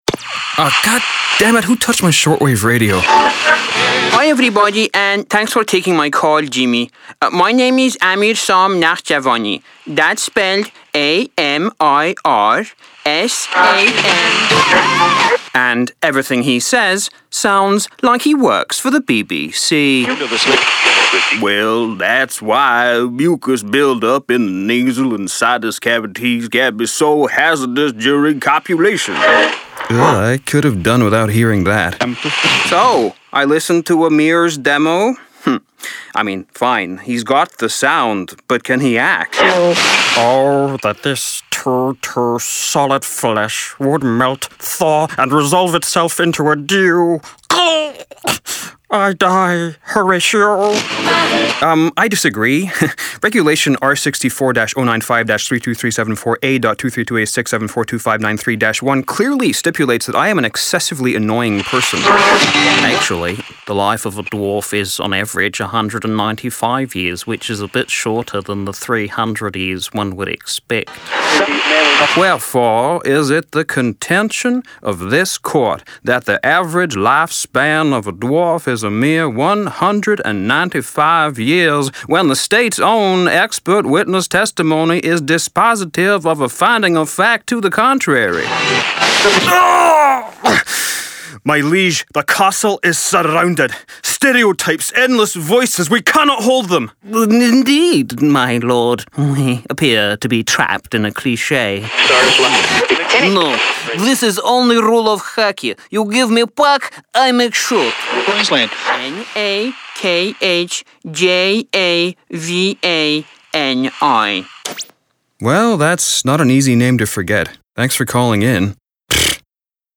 Démo voix - ANG